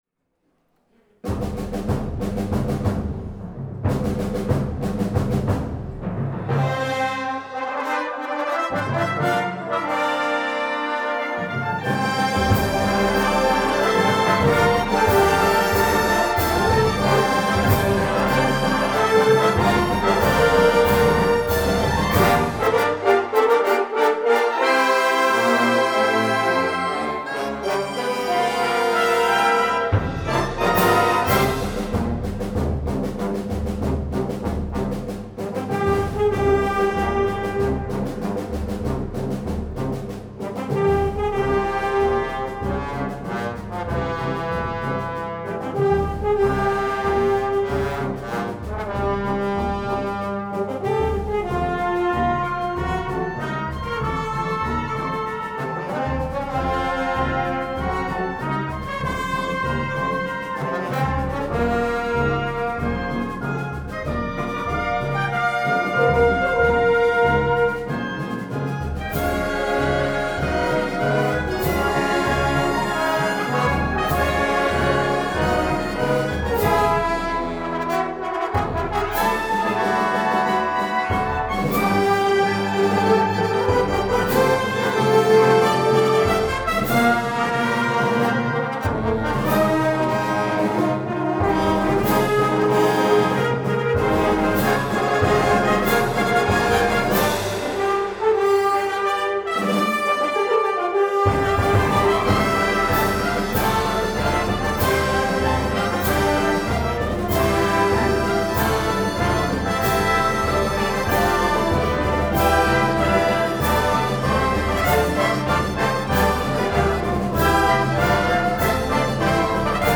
Pasodoble
Gender: Two-step